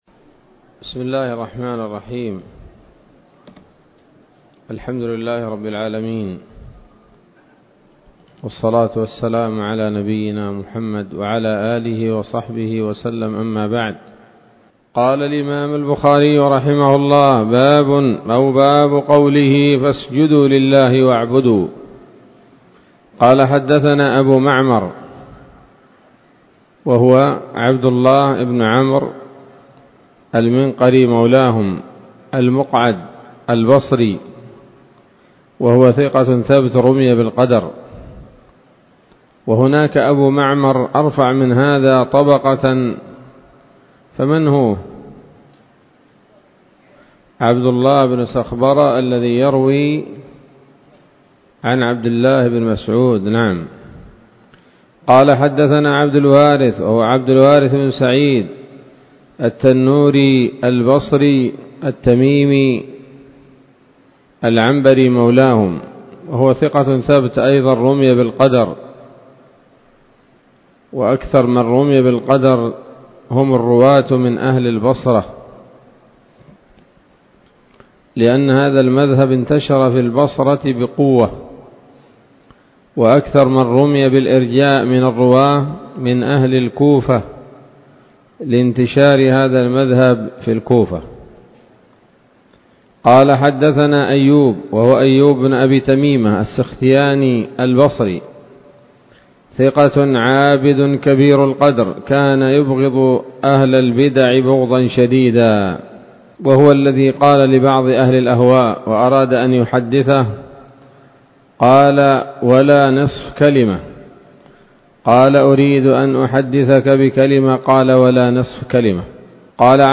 الدرس الخامس والأربعون بعد المائتين من كتاب التفسير من صحيح الإمام البخاري